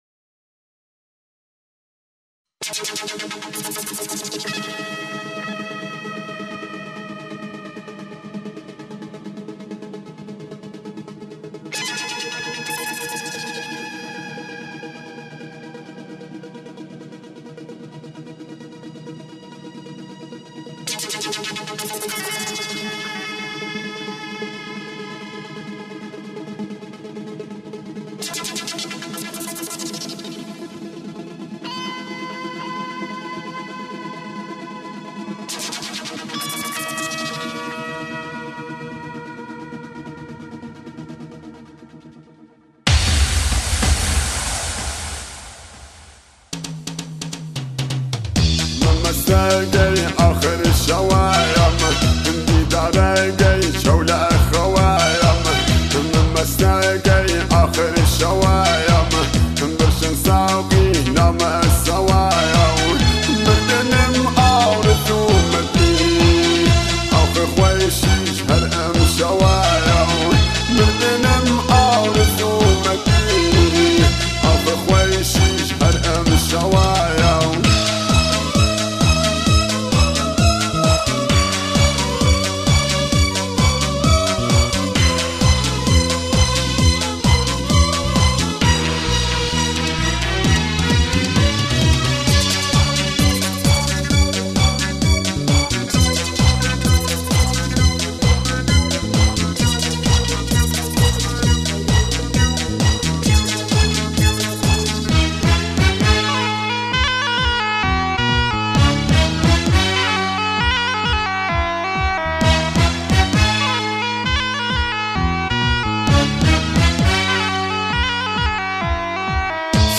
Kurdish music Lak music
آهنگ کردی شاد